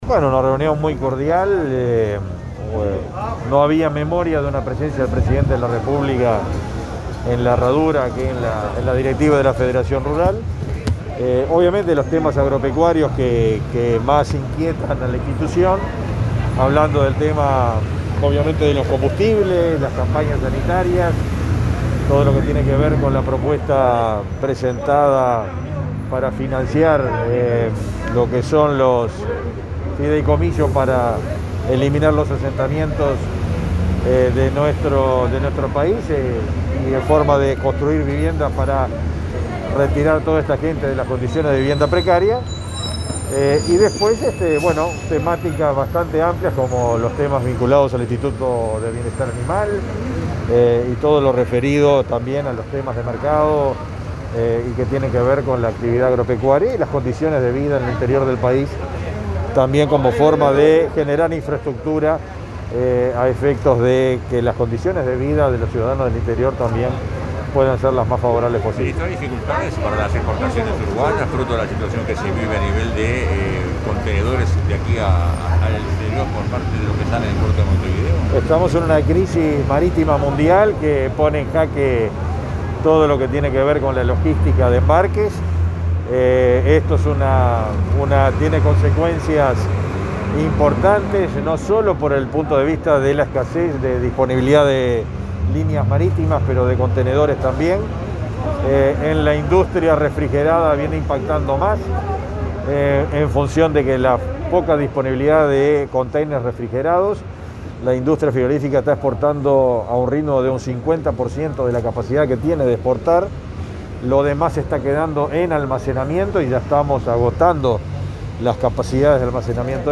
Declaraciones a la prensa del ministro de Ganadería, Fernando Mattos
Al finalizar, Mattos efectuó declaraciones a la prensa.